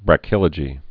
(bră-kĭlə-jē)